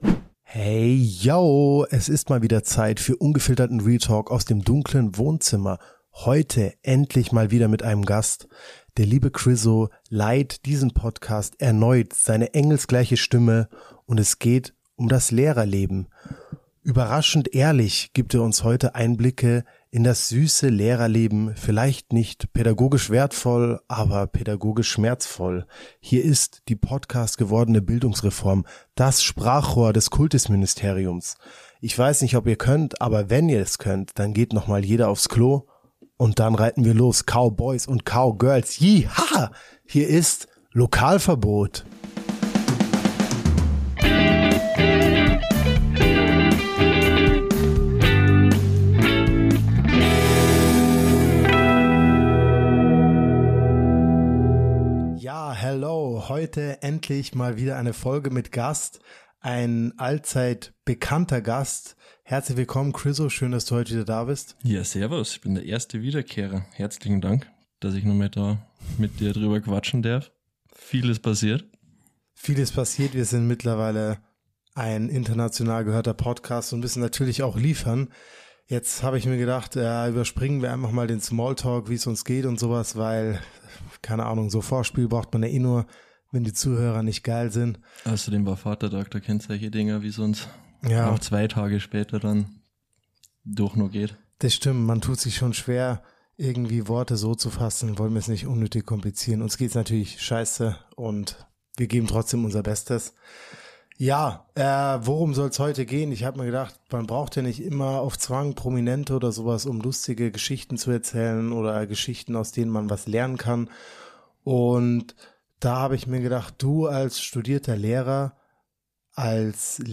Dieser Podcast ist eine Art Podiumsdiskussion der Hochbegabten.
Zwei Menschen, die sich selbst nicht zu ernst nehmen, sprechen über Schule, Bildung – und all das, was dazwischen liegt.